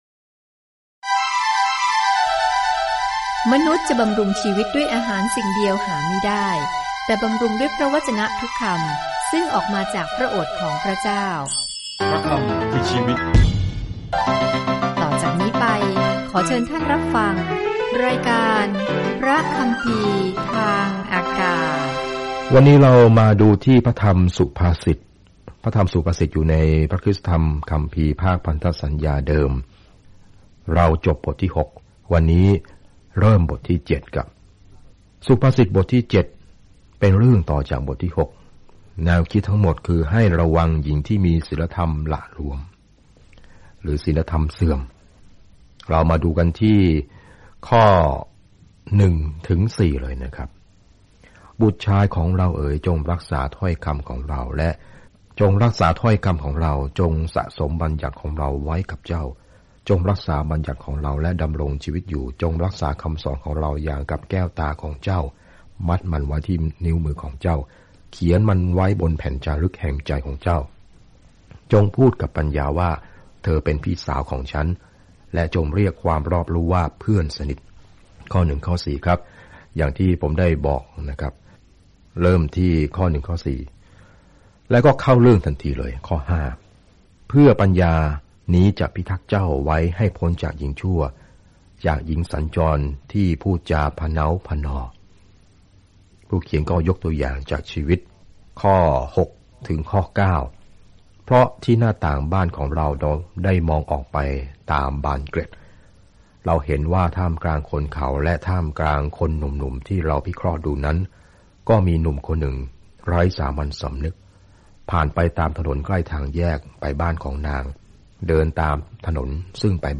สุภาษิตเป็นประโยคสั้นๆ ที่ดึงมาจากประสบการณ์อันยาวนานที่สอนความจริงในลักษณะที่ง่ายต่อการจดจำ - ความจริงที่ช่วยให้เราตัดสินใจได้อย่างชาญฉลาด เดินทางผ่านสุภาษิตทุกวันในขณะที่คุณฟังการศึกษาด้วยเสียงและอ่านข้อที่เลือกสรรจากพระวจนะของพระเจ้า